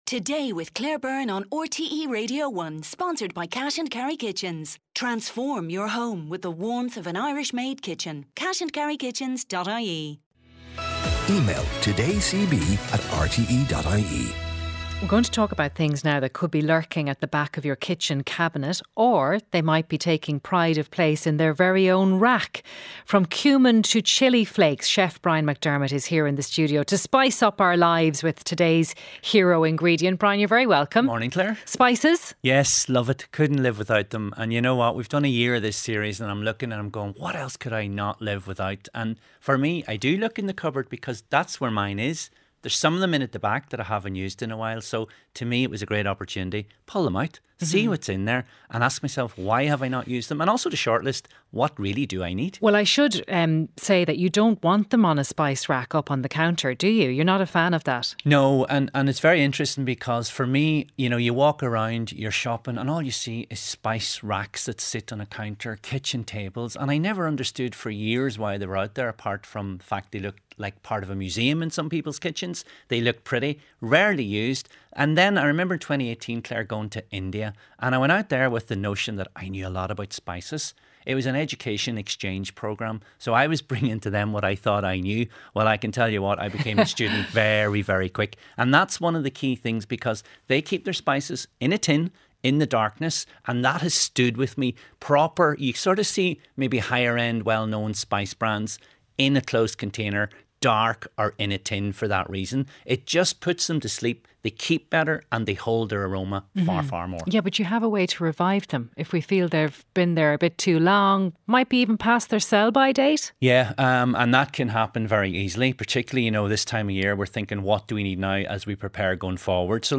Highlights from the mid-morning show with Claire Byrne, featuring stories of the day, sharp analysis, features, sports and consumer interest items.